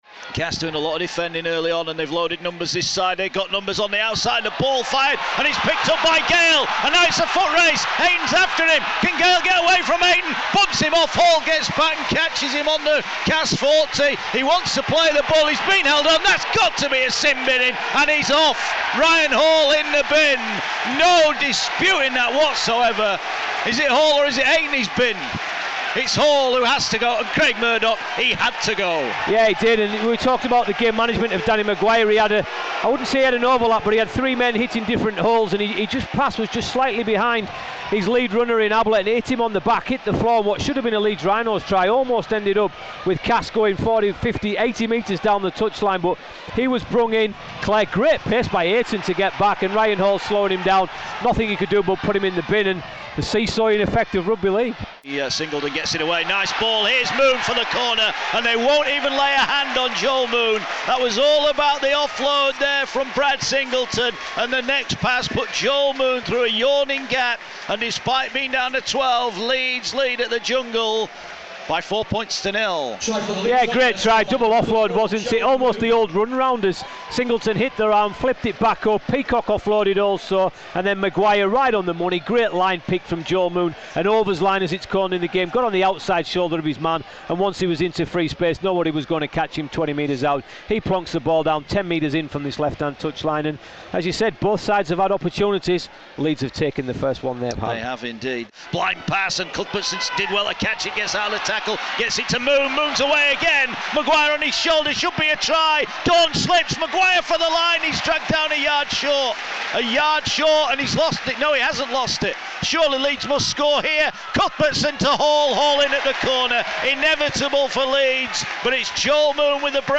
Highlights of a cracking Good Friday derby between Castleford Tigers and Leeds Rhinos, plus reaction from Brian McDermott, Daryl Powell, Kallum Watkins & Luke Dorn. Commentators: